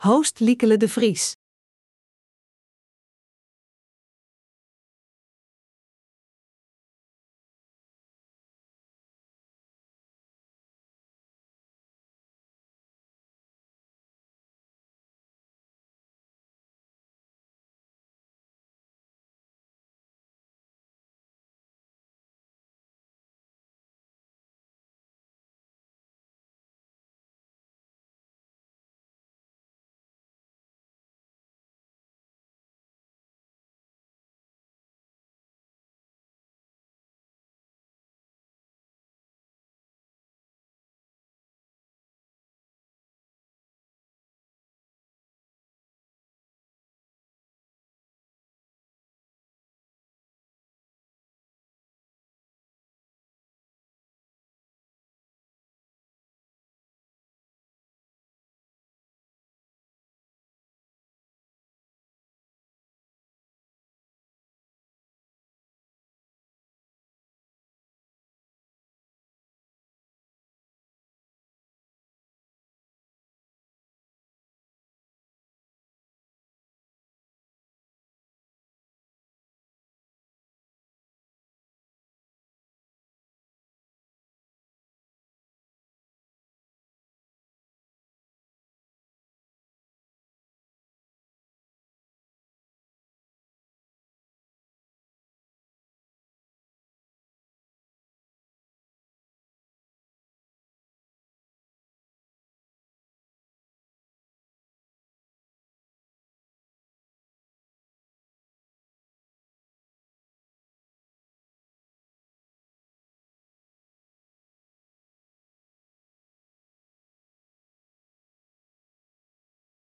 RADIO Webinar: Ontdek Neurotechnologie deel 3 over Neurorechten